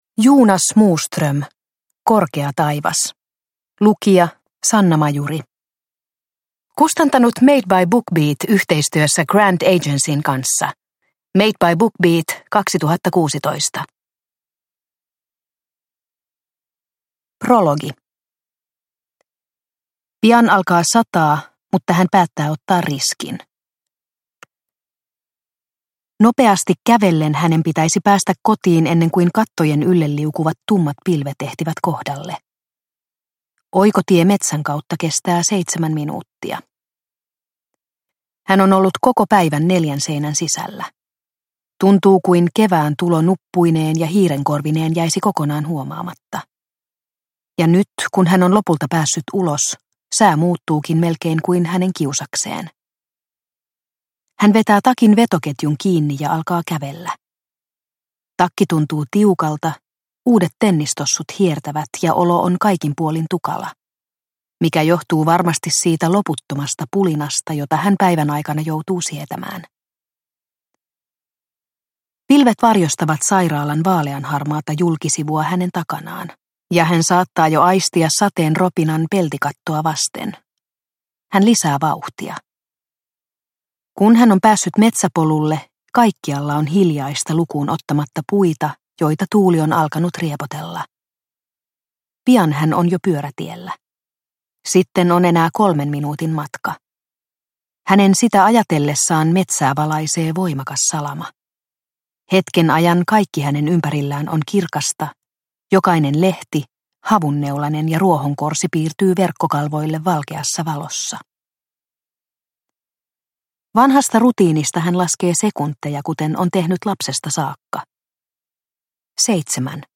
Korkea taivas – Ljudbok – Laddas ner